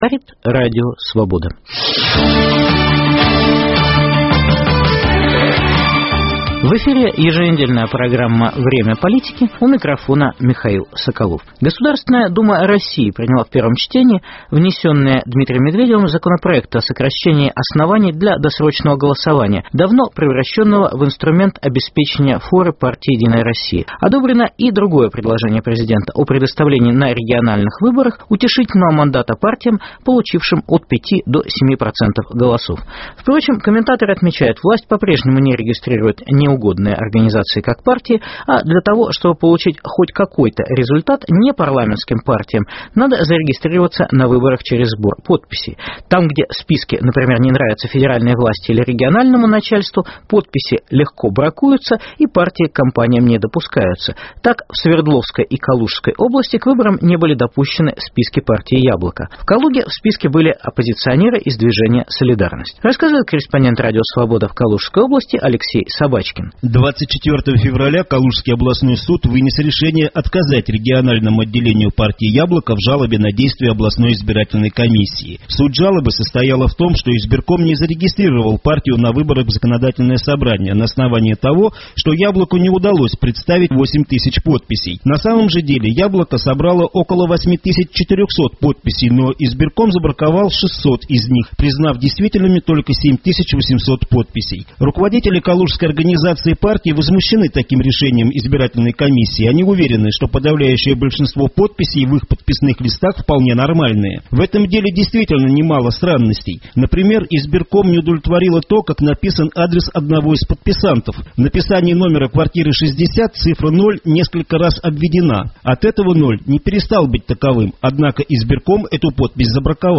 Как работает "административный ресурс" на выборах в Калужской области, Омске, Иркутске, Ульяновске. Есть ли у "Единой России" конкуренты на выборах Думы Воронежской областной думы? Репортаж из Воронежа